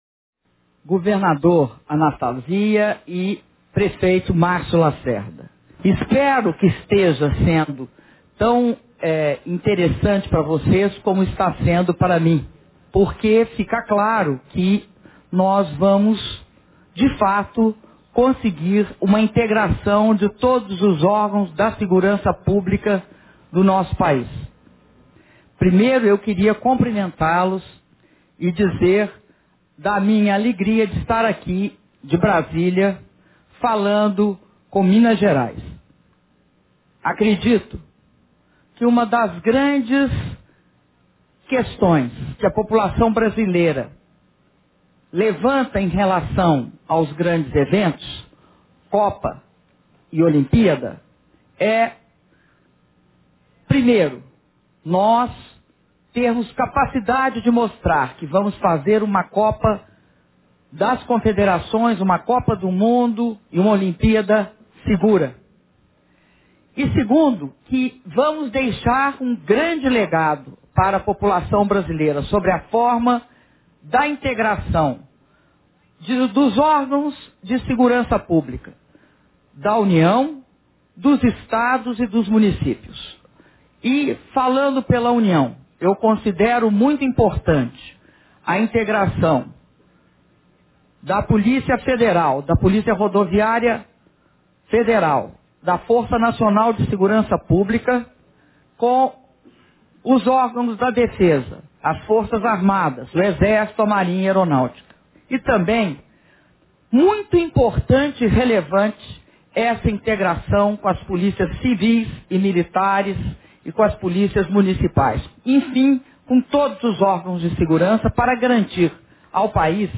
Palavras da Presidenta da República, Dilma Rousseff, durante visita ao Centro de Comando e Controle para Segurança de Grandes Eventos
Departamento de Polícia Federal – Brasília-DF, 13 de junho de 2013